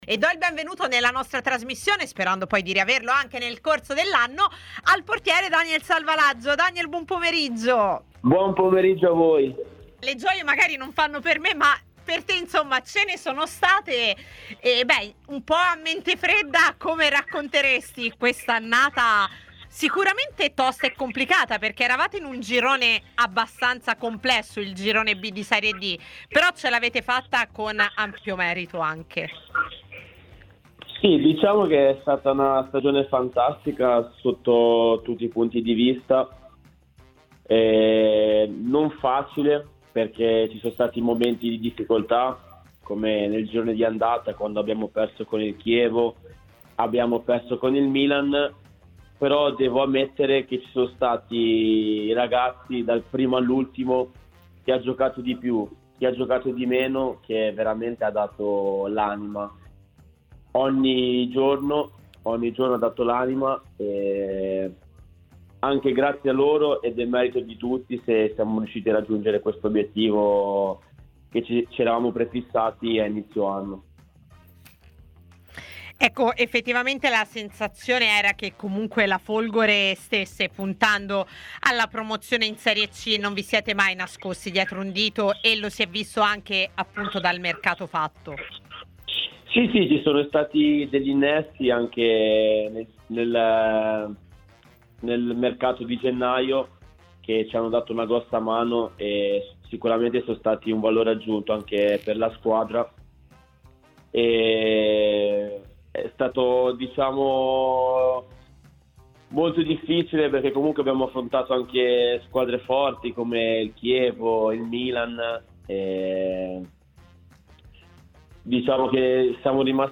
trasmissione in onda su TMW Radio.